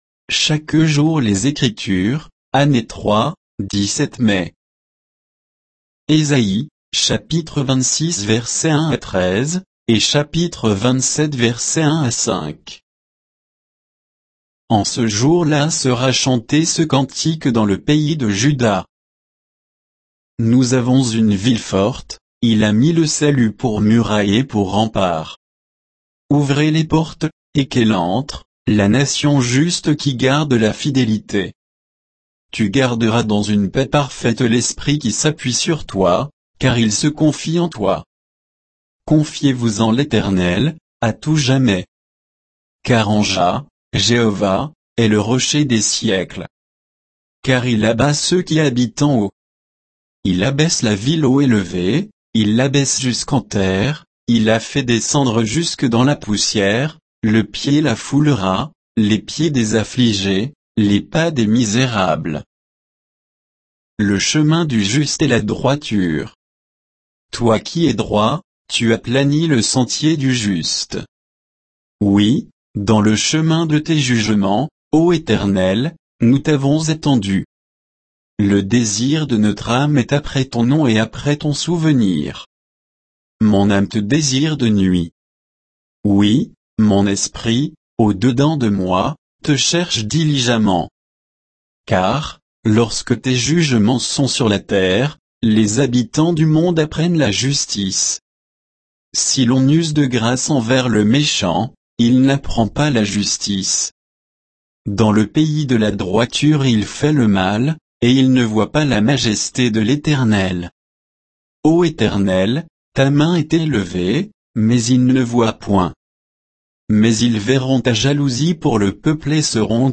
Méditation quoditienne de Chaque jour les Écritures sur Ésaïe 26, 1 à 13; 27, 1-5